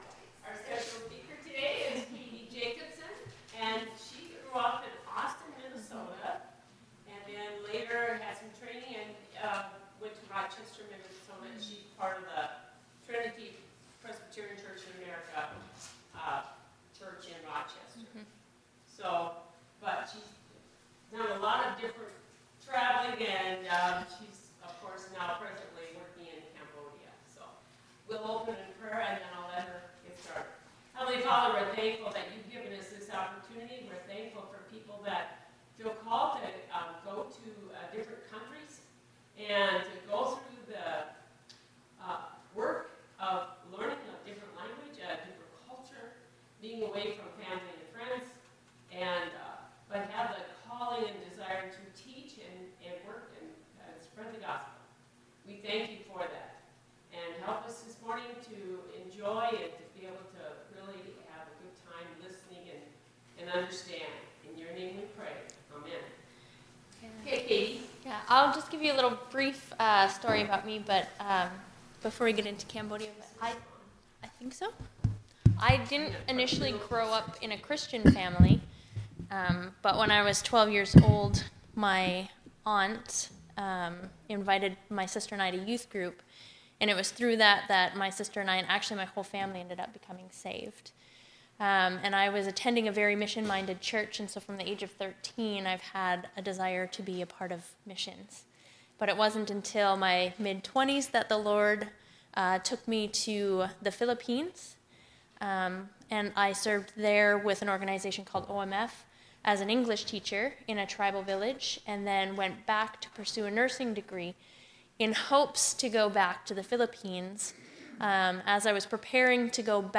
Report on Mission Work in Cambodia – Pollock Memorial Presbyterian Church
for Sunday School Class